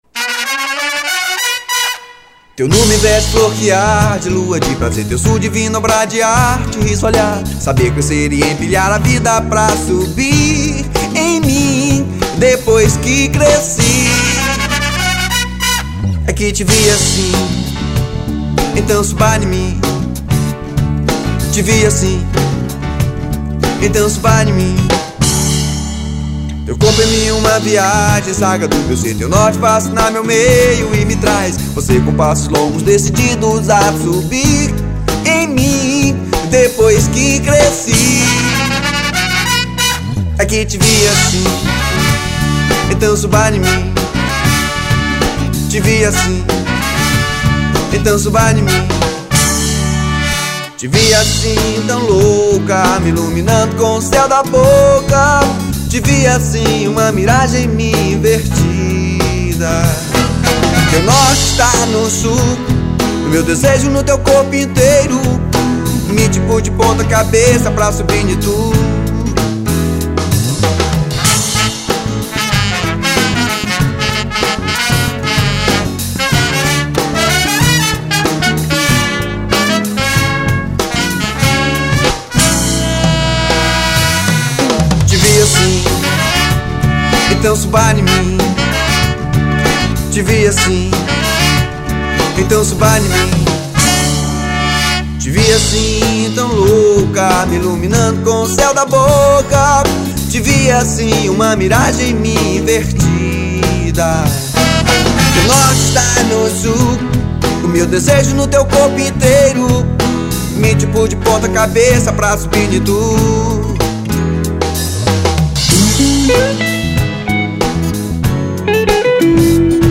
1986   02:33:00   Faixa:     Rock Nacional